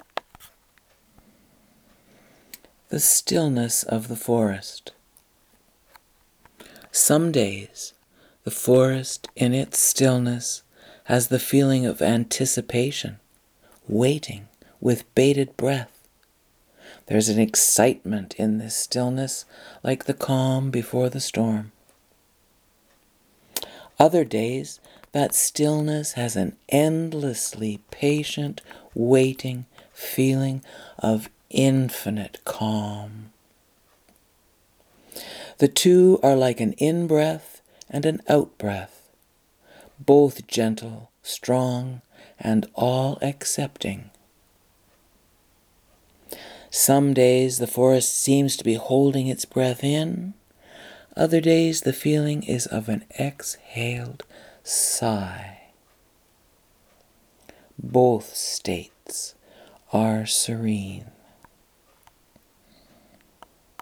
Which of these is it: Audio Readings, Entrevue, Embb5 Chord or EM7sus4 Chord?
Audio Readings